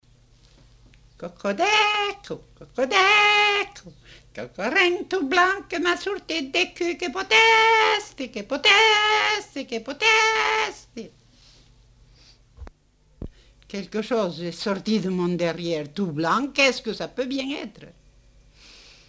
Mimologisme de la poule